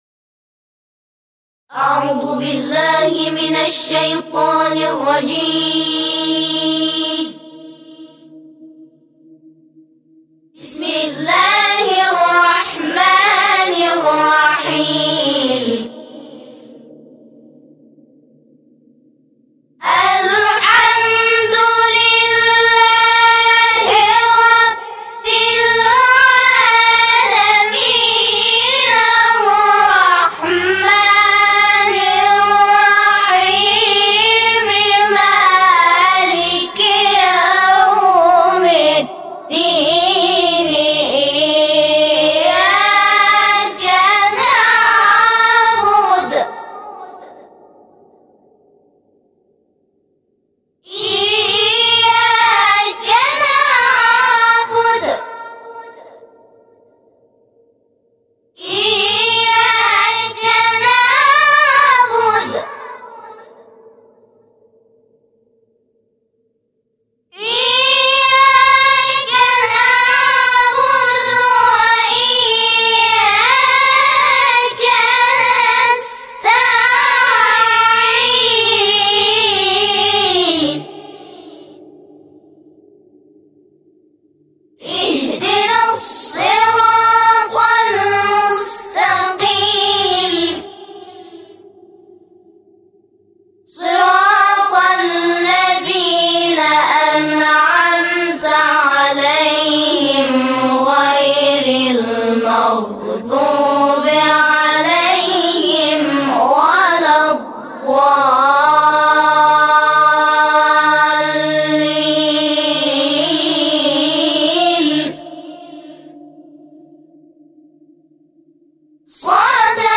دانلود نماهنگ "همخوانی سوره حمد" تلاوت شحات انور کاری از گروه تواشیح بین المللی تسنیم اصفهان
در این بخش از ضیاءالصالحین، نماهنگ "همخوانی سوره حمد" تلاوت شحات انور، کاری از گروه تواشیح بین المللی تسنیم اصفهان را به مدت 2 دقیقه با علاقه مندان به اشتراک می گذاریم.